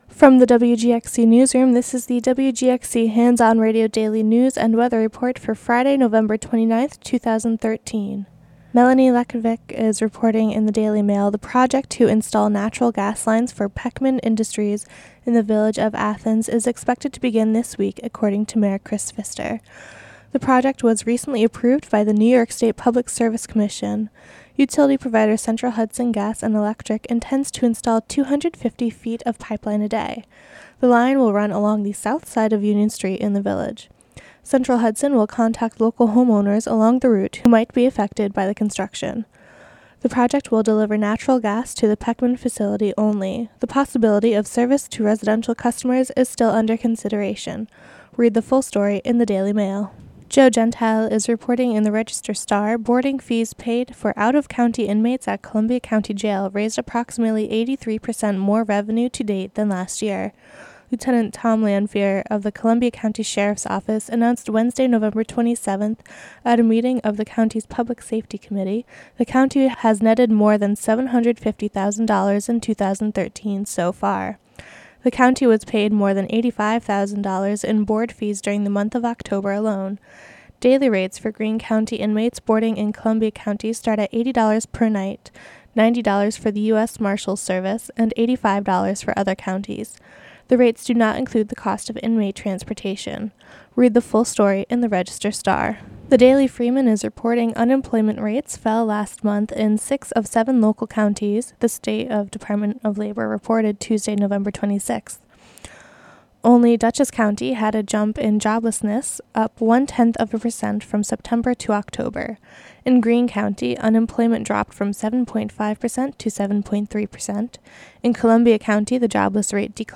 Local news and weather for Friday, November 29, 2013.